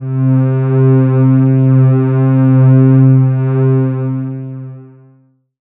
37i01pad1-c.wav